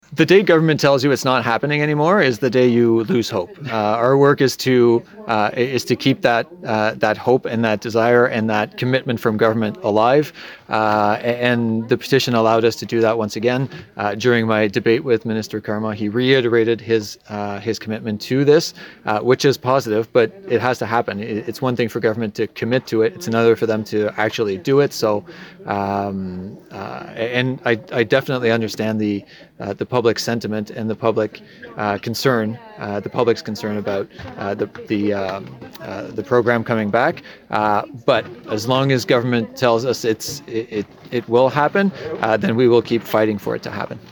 The full interview with Fortin is available below: